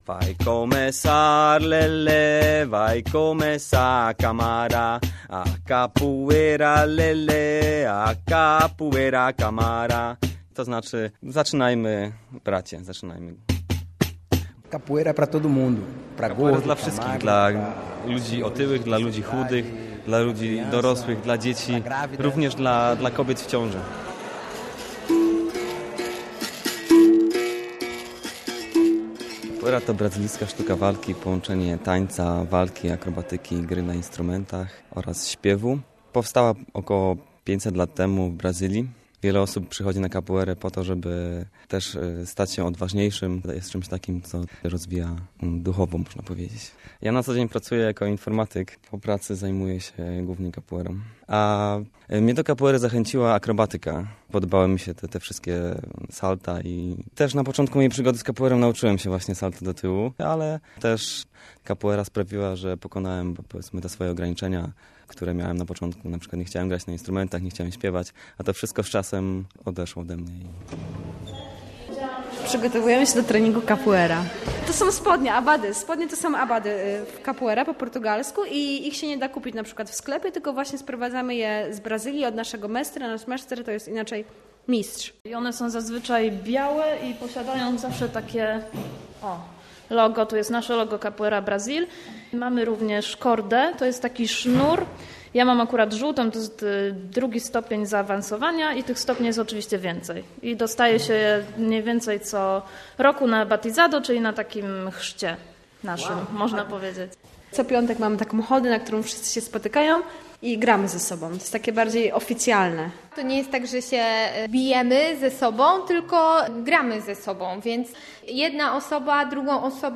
Capoeira Brasil - reportaż